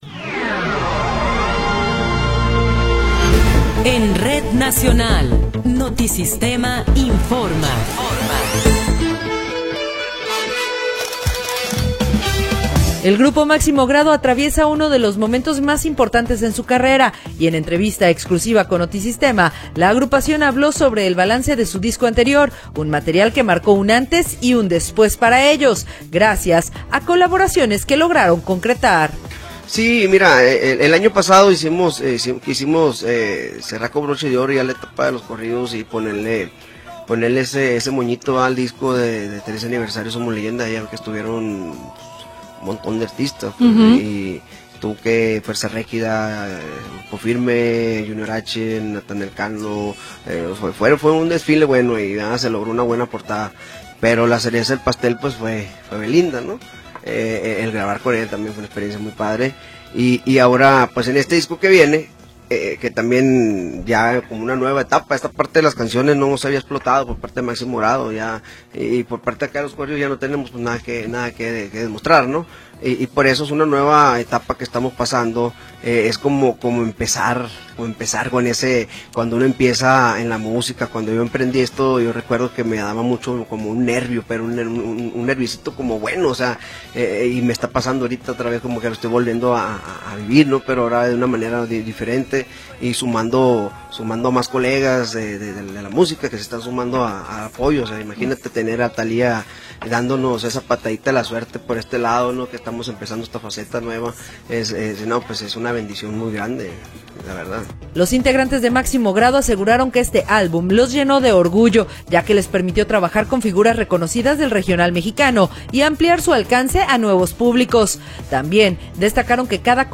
Noticiero 10 hrs. – 19 de Abril de 2026
Resumen informativo Notisistema, la mejor y más completa información cada hora en la hora.